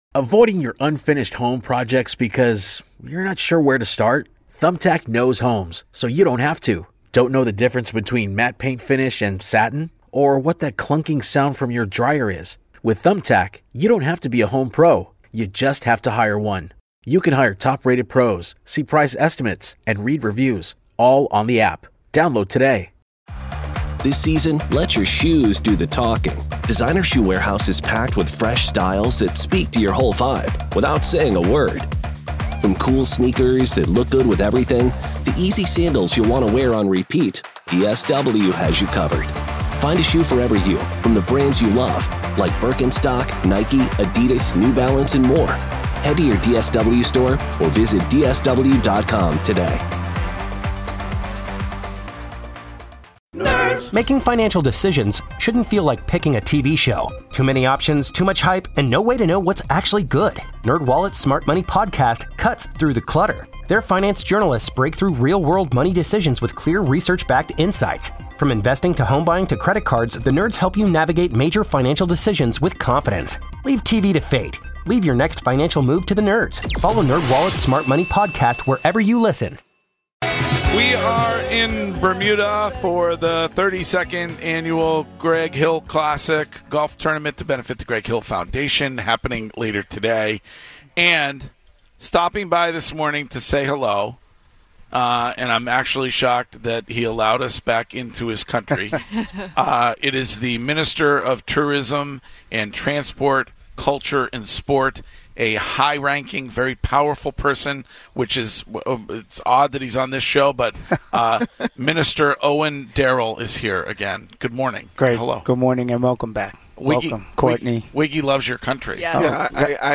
HR 2 - Welcomed to Bermuda by the Ministry of tourism